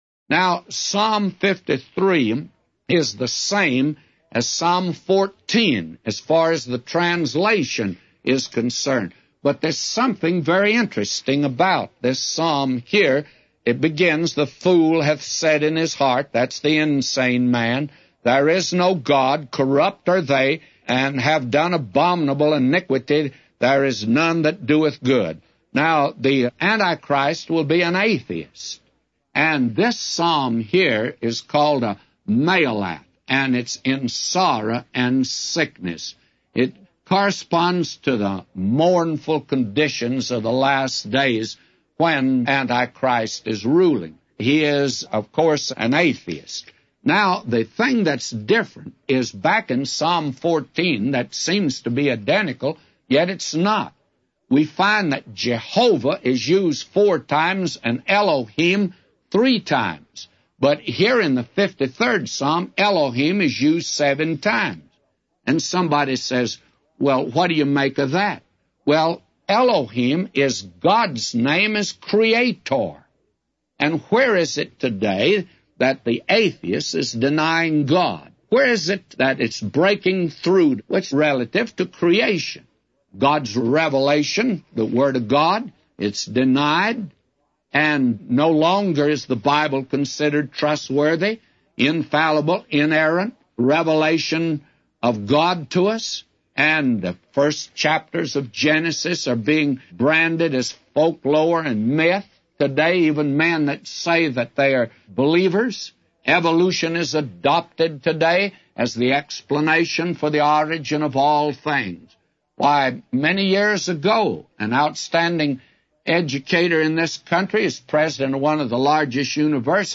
A Commentary By J Vernon MCgee For Psalms 53:1-999